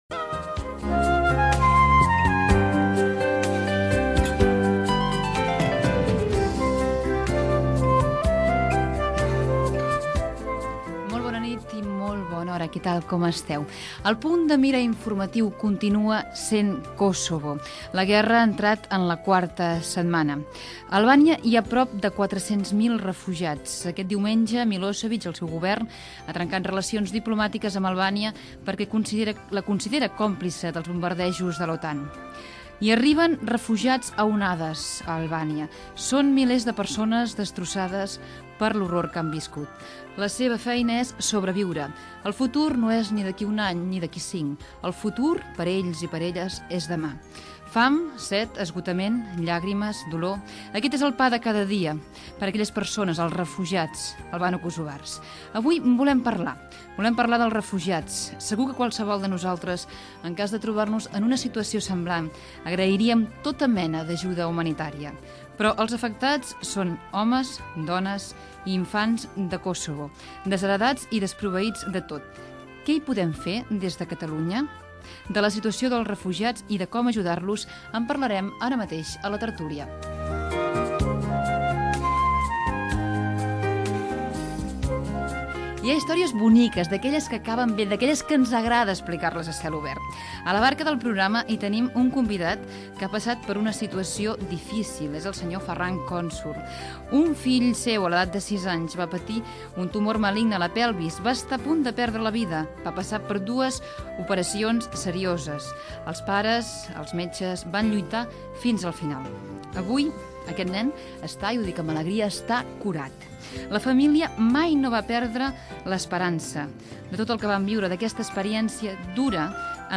Presentació, sumari de continguts, hora, equip i indicatiu del programa
FM